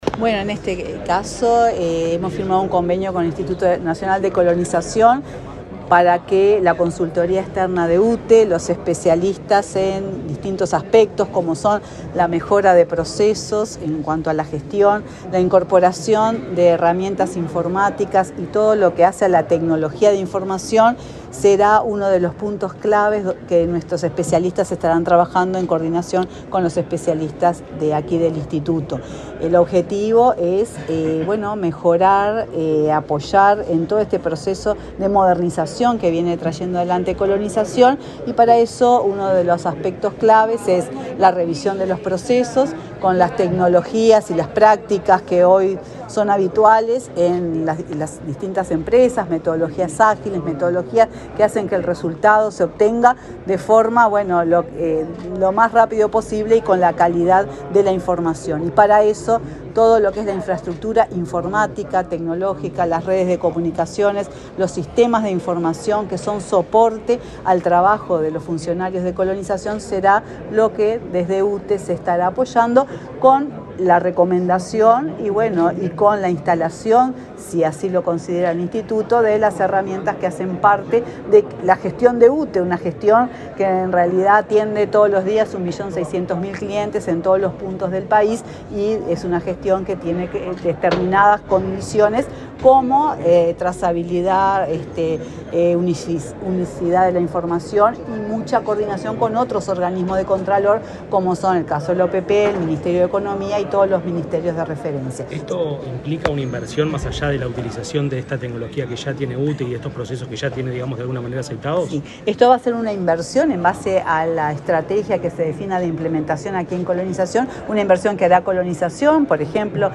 Declaraciones de la presidenta de UTE, Silvia Emaldi
Este miércoles 9, en Montevideo, la UTE y el Instituto Nacional de Colonización firmaron un acuerdo que contempla la ayuda de Conex al INC. La titular del ente energético, Silvia Emaldi, dialogó con la prensa y destacó la colaboración entre ambos organismos.